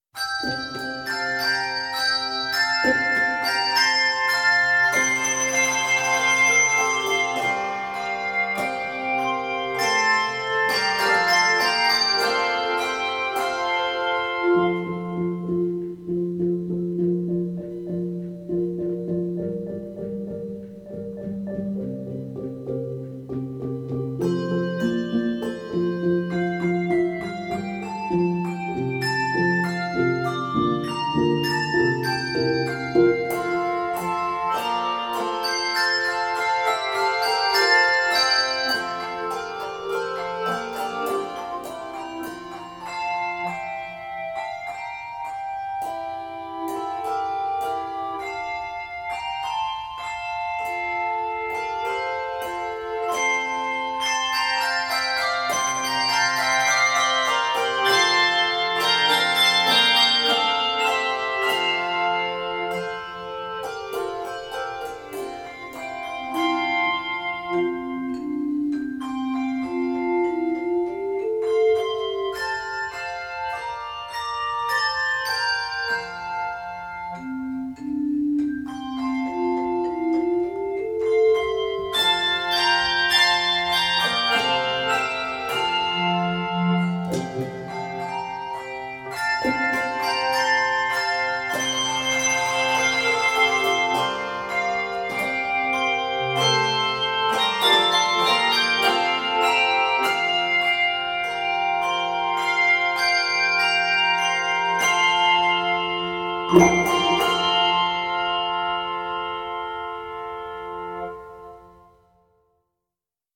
A stately arrangement
Key of F Major.